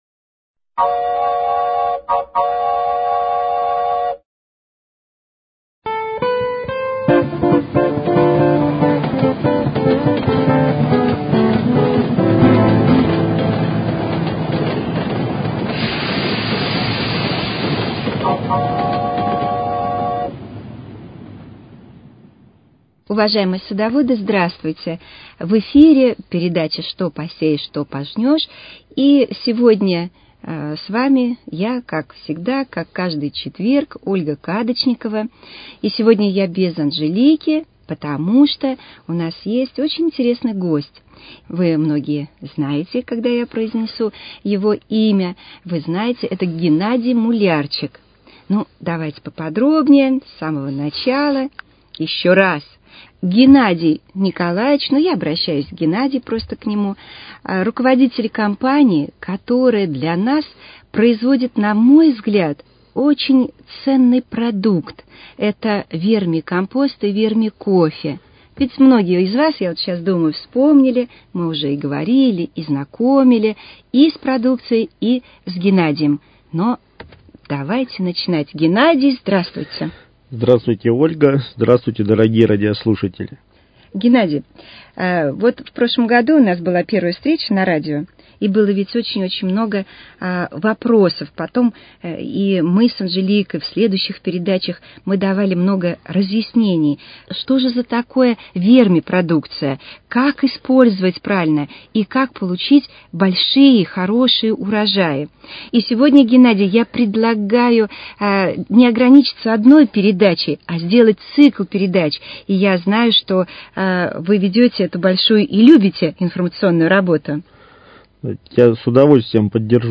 Что посеешь, то пожнешь: Беседа